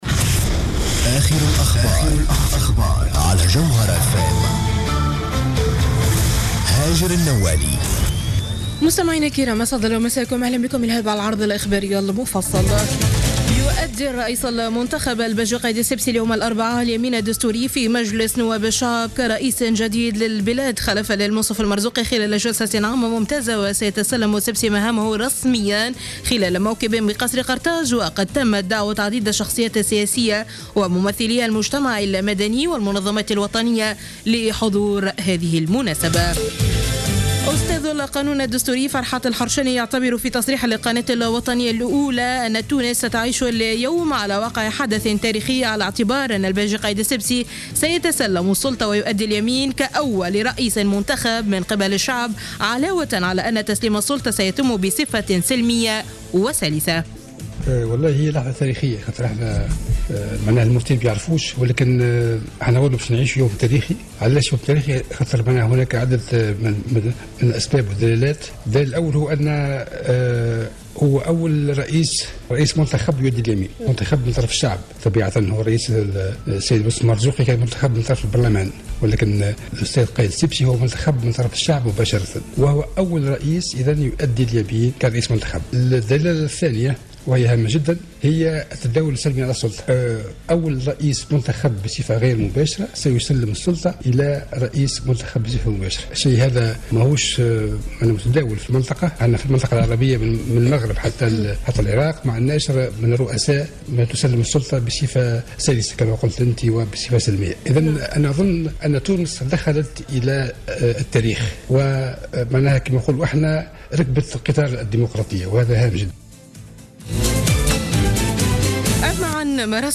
نشرة اخبار منتصف الليل ليوم الإربعاء 31 ديسمبر 2014